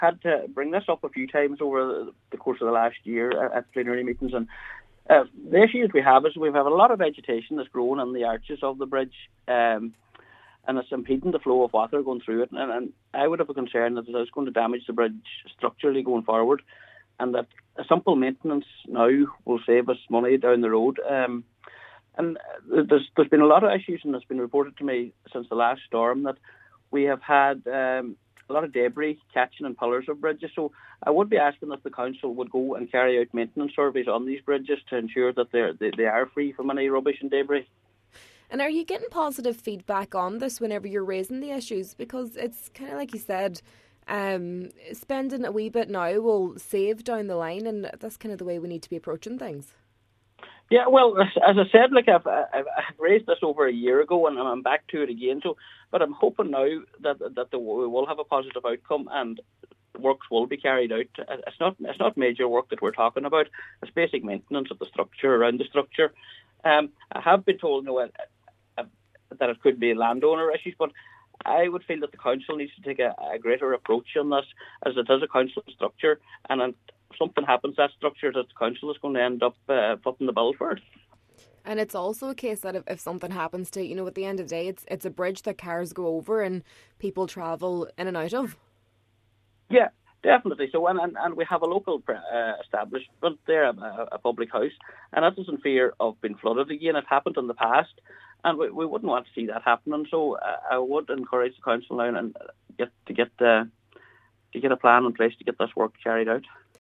He is now calling for an assessment of the bridge to ensure it is properly maintained: